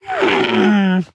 SFX_LOE_020_Death.ogg